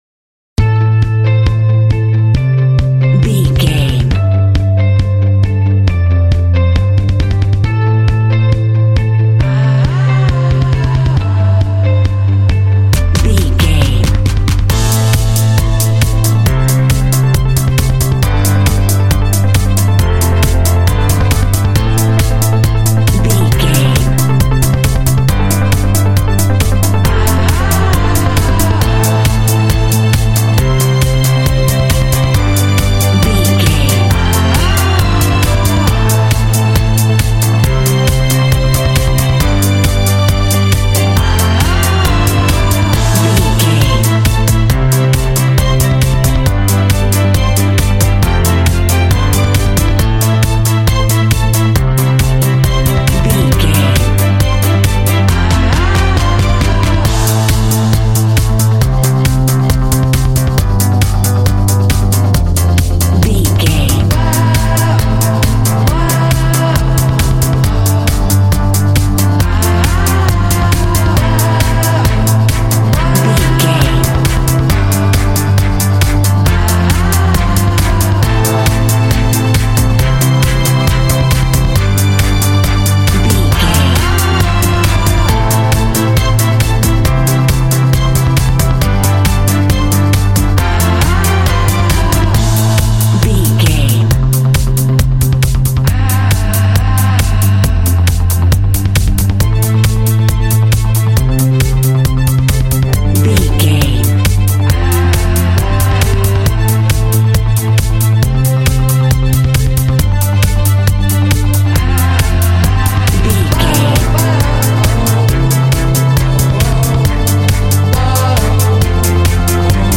Epic / Action
Fast paced
Aeolian/Minor
driving
energetic
bass guitar
drums
synthesiser
electric guitar
strings
vocals
synth pop
alternative rock
indie